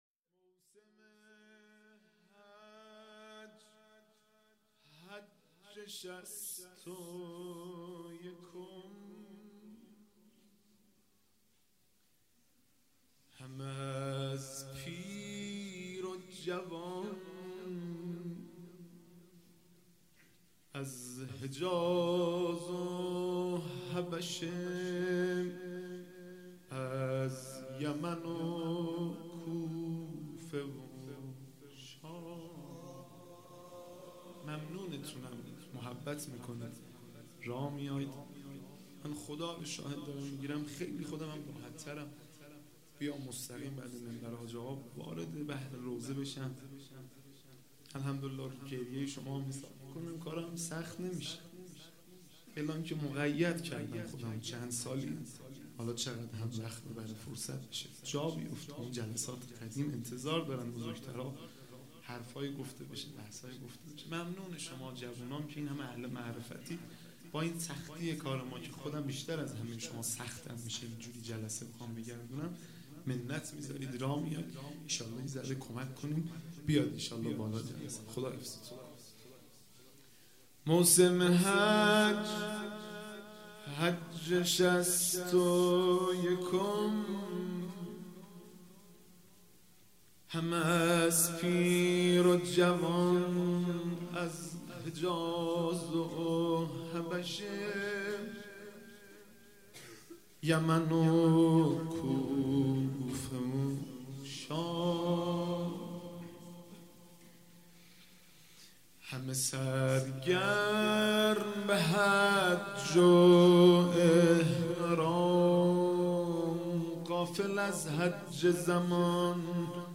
عزاداری شب دوم محرم
فایل آن‌را از اینجا دانلود کنید: audio/mp3 روضه مرورگر شما از ویدئو پشتیبانی نمی‌کند. فایل آن‌را از اینجا دانلود کنید: audio/mp3 زمینه مرورگر شما از ویدئو پشتیبانی نمی‌کند. فایل آن‌را از اینجا دانلود کنید: audio/mp3 واحد مرورگر شما از ویدئو پشتیبانی نمی‌کند. فایل آن‌را از اینجا دانلود کنید: audio/mp3 شور